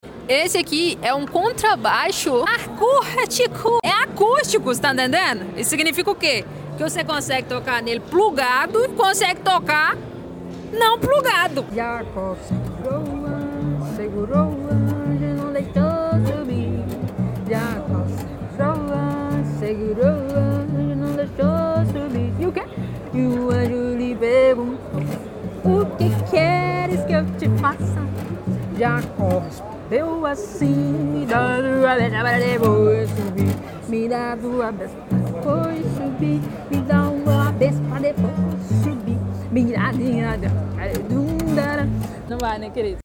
Contrabaixo acústico sound effects free download